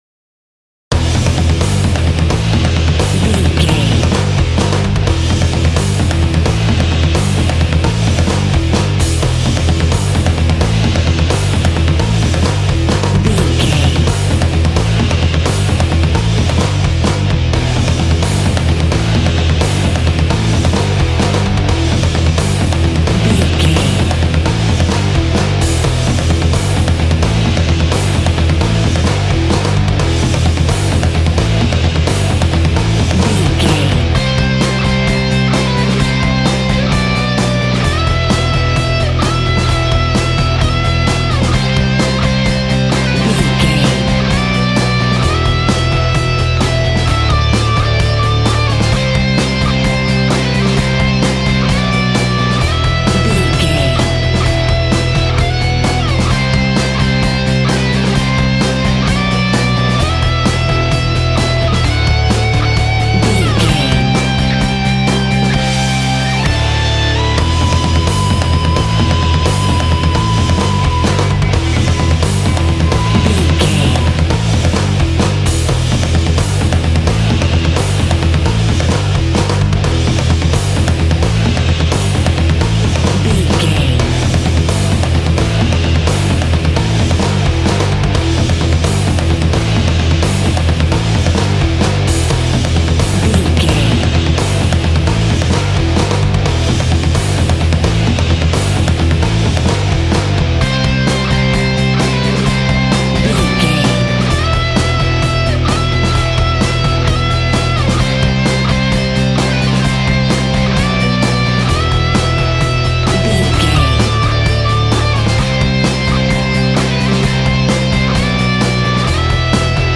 Aeolian/Minor
electric guitar
drums
bass guitar